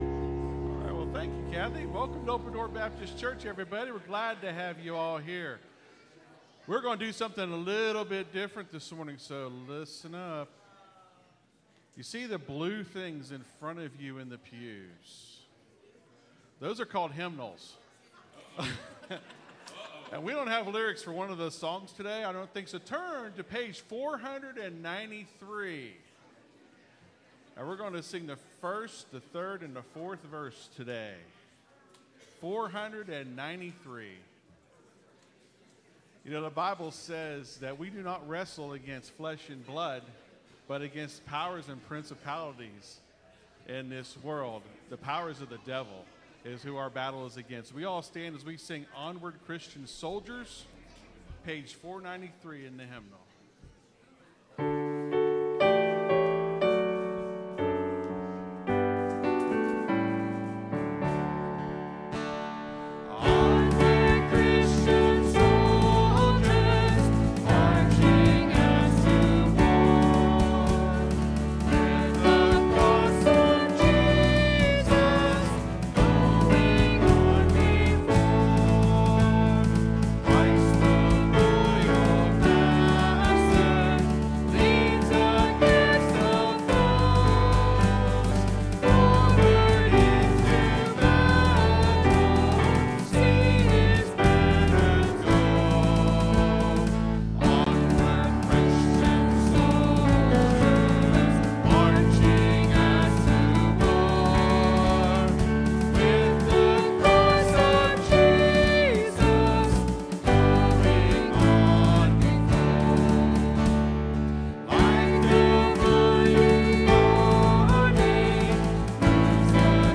(Sermon starts at 24:30 in the recording).